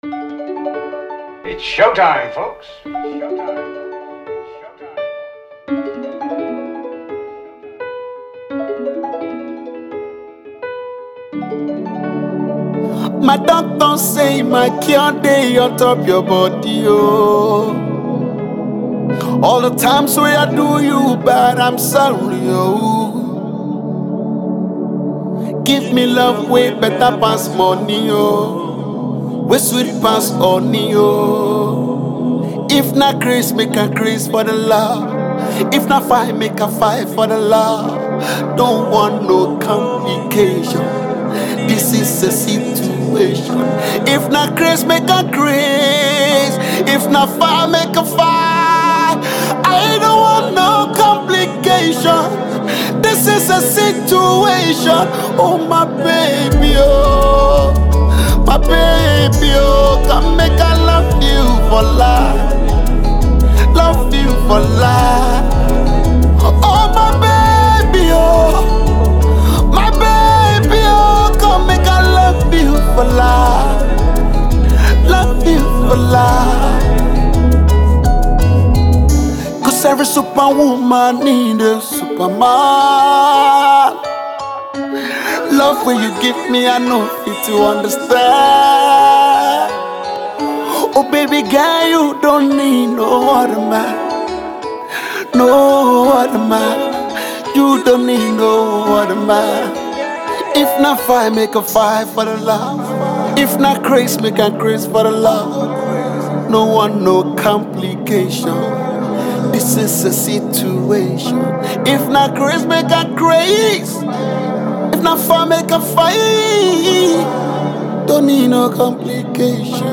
an alternate and high life cover